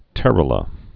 (tĕrə-lə)